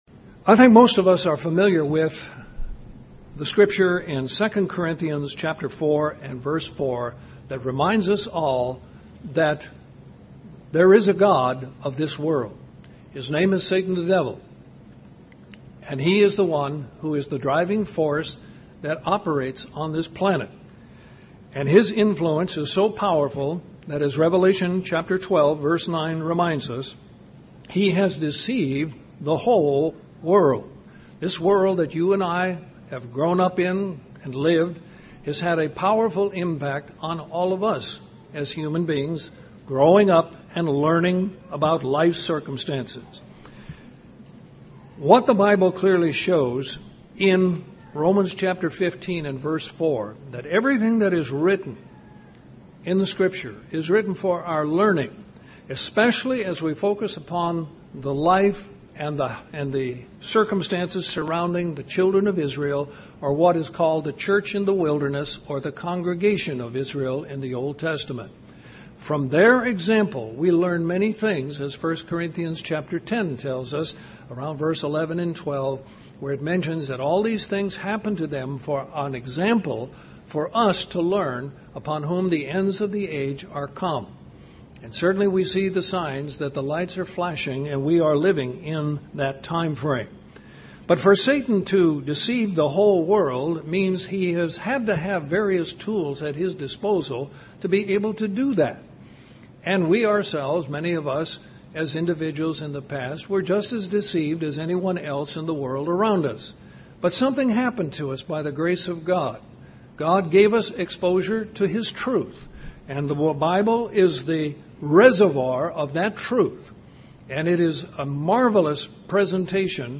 As people we hunger for knowledge, but often go to the wrong sources. This sermon delves into some of these sources and redirects us to the true path.